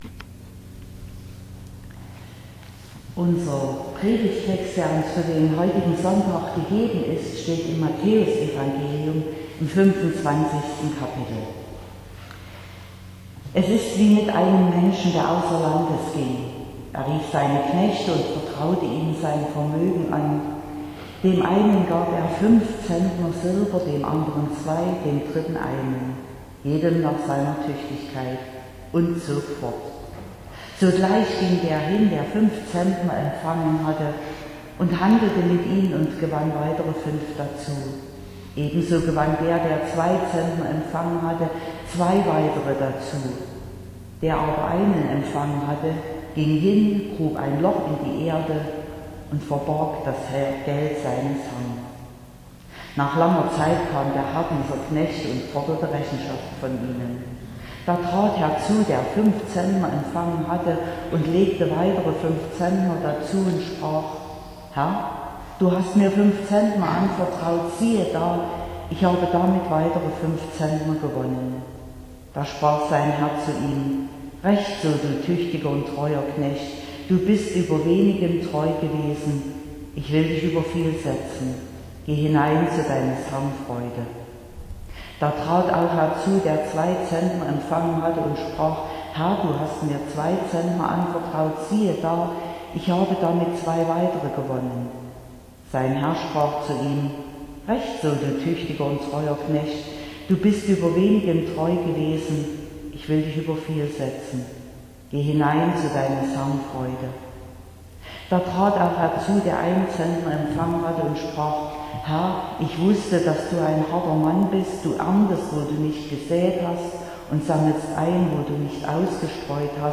14.08.2022 – Gottesdienst
Predigt und Aufzeichnungen